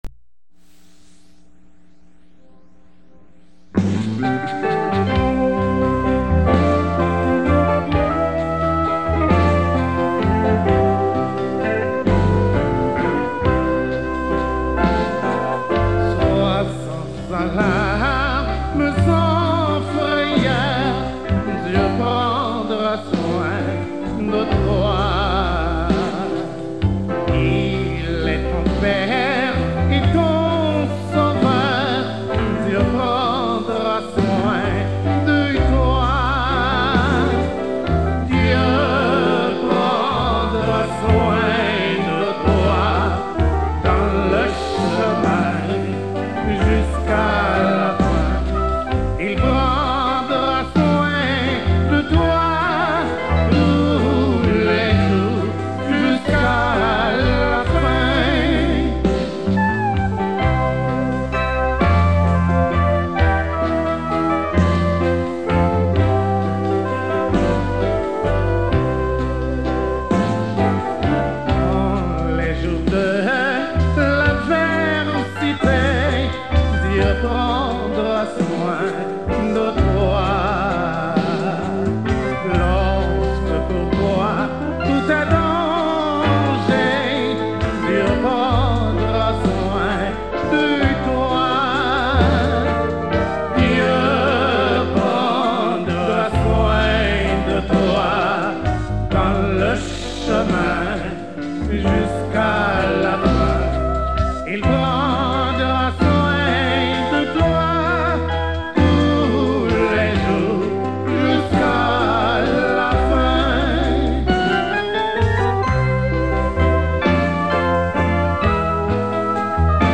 2022 CHANTS MYSTIQUES audio closed https